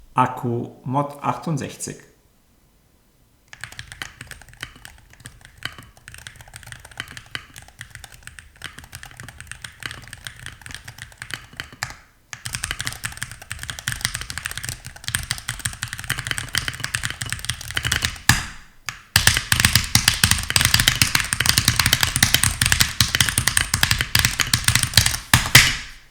Der Klang der Mod68 HE ist klar und fokussiert mit hörbaren Anschlägen, dabei aber kontrolliert. Nachhall fehlt, die Taster klingen angenehm satt. Dank der Kombination aus Poron-Sandwichschaum, Bodenschaum und Silikondämpfung entsteht ein klick-klackendes Klangbild mit wahrnehmbaren, aber dezenten Anschlägen.
Hall wird fast komplett eliminiert, nur die Leertaste sticht mangels zusätzlichem Schaumstoff hervor.
Deutlich gesagt: Das Klangprofil klingt Premium, ist angenehm, ruhig und voll alltagstauglich – nur nicht ultra-silent.